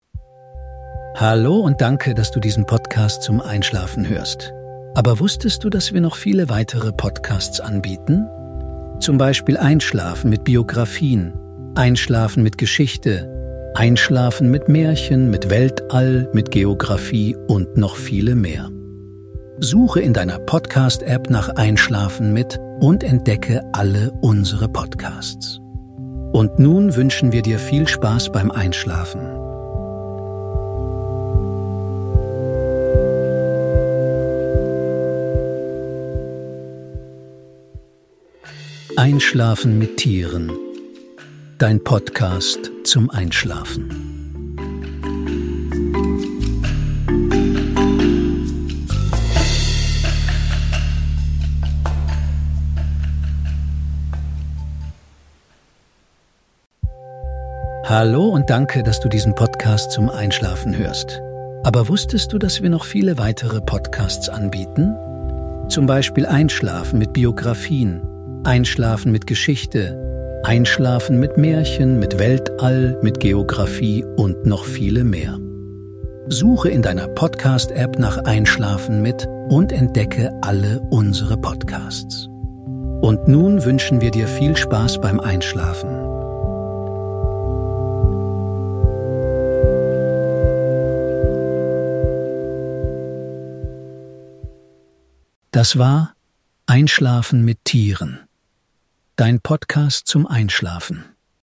Wir lesen dir über Tiere zum Einschlafen vor...